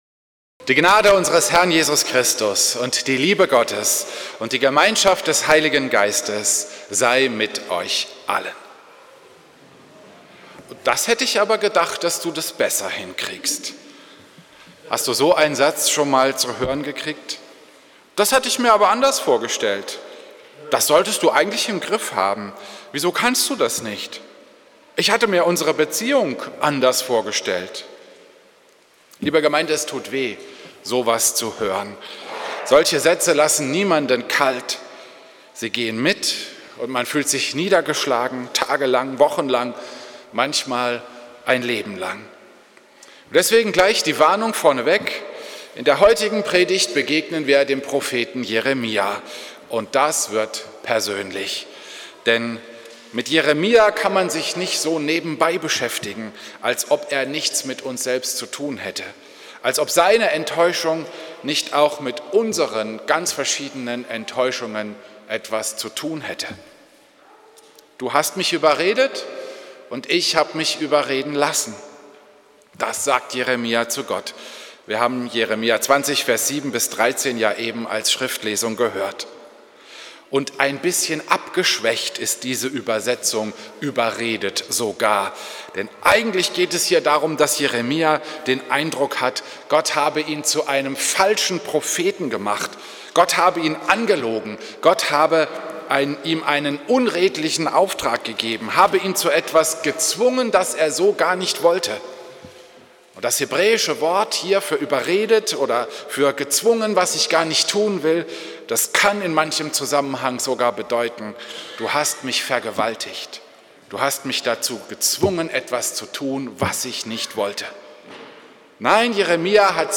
Predigt am Sonntag Okuli